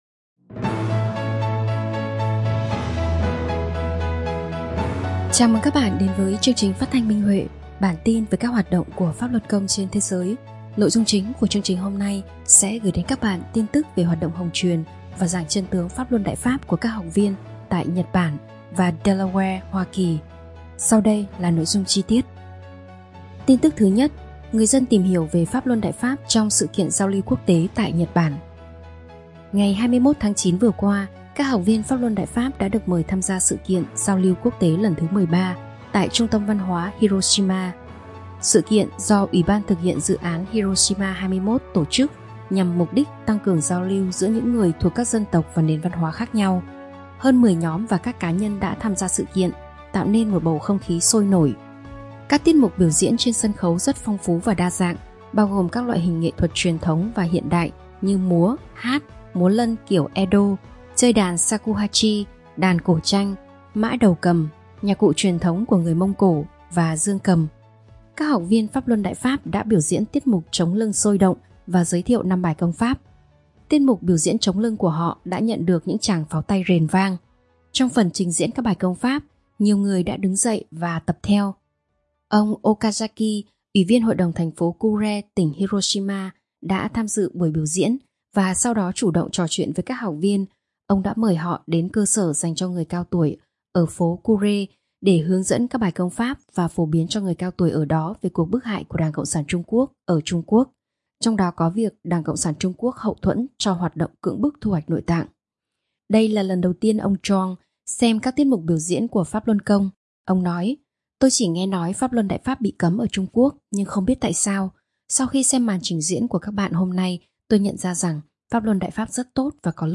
Chương trình phát thanh số 380: Tin tức Pháp Luân Đại Pháp trên thế giới – Ngày 26/9/2025